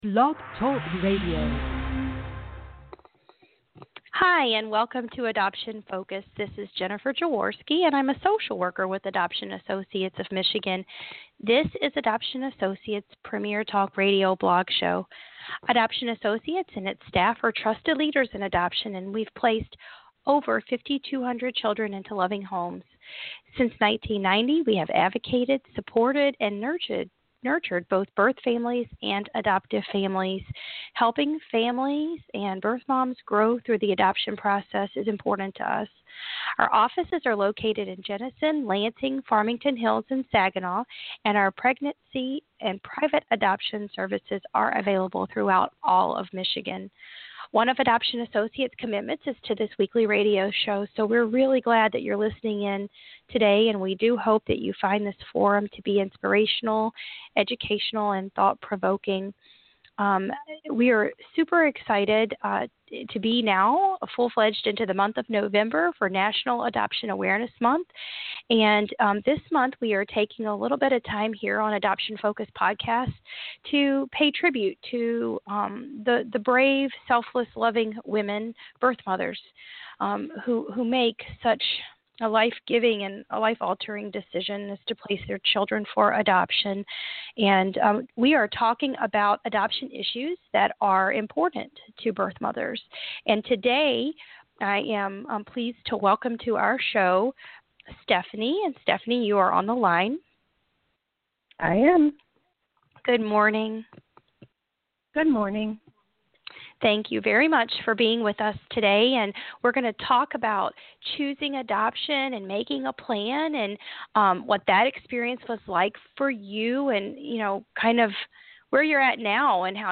Blogtalk Radio Transcript: “I Chose Adoption” (You may listen to the podcast by clicking on the audio link, or read the transcript of the podcast below.)